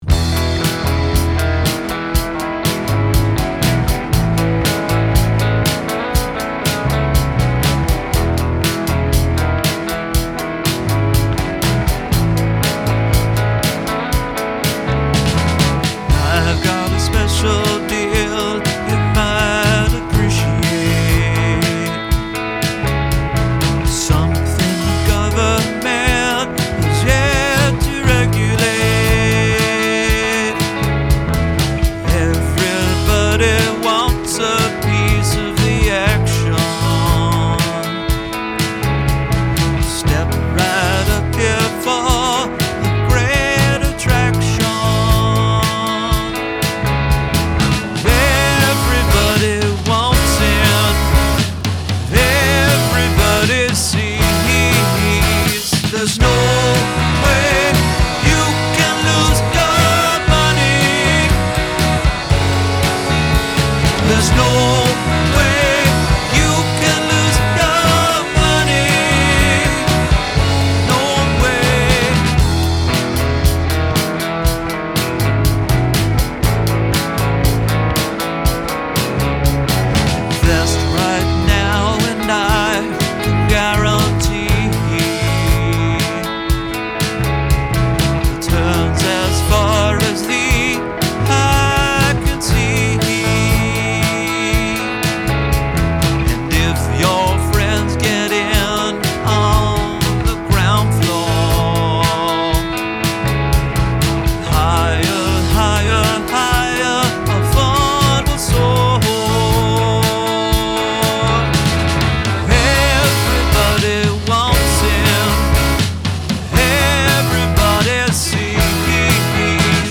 Drums, Bass, Percussion, Vox, Guitar